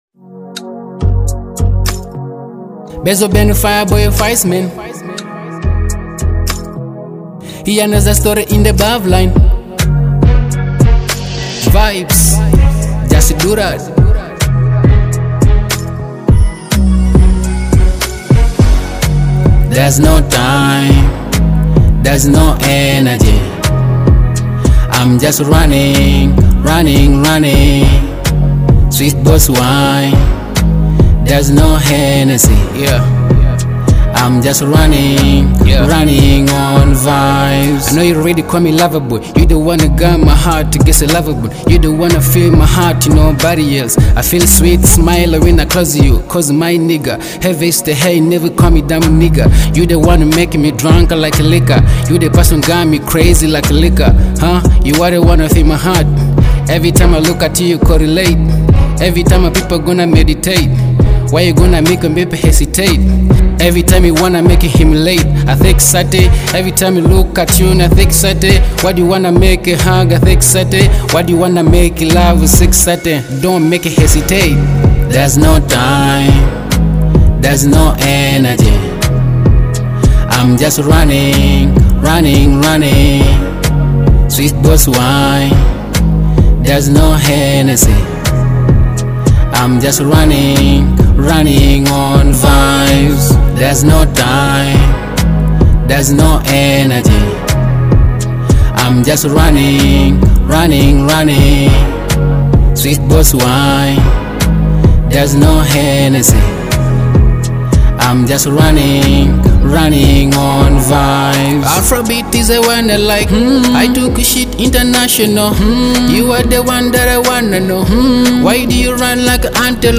uplifting Ugandan track built around pure energy
With catchy lines and a lively delivery
Driven by an infectious rhythm and feel-good atmosphere